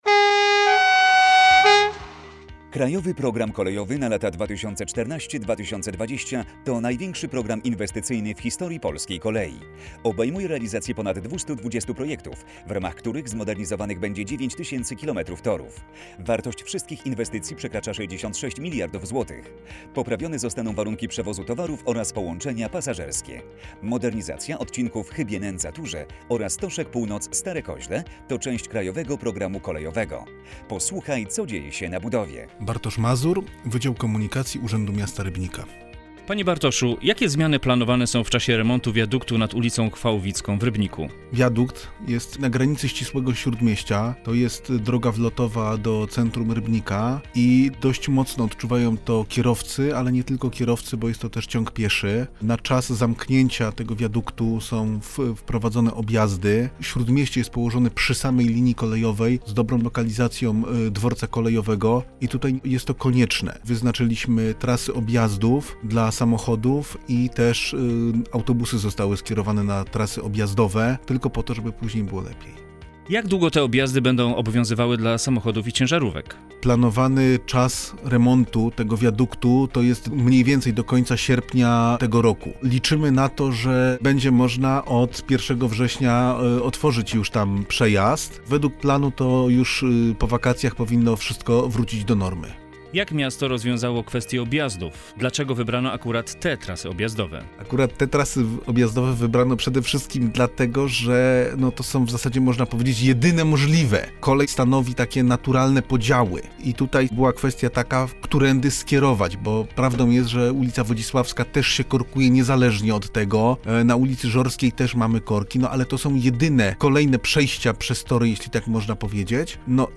audycja radiowa